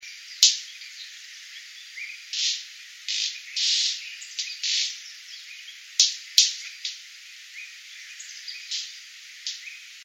White-bearded Manakin (Manacus manacus)
Life Stage: Adult
Location or protected area: Reserva Privada y Ecolodge Surucuá
Condition: Wild
Certainty: Photographed, Recorded vocal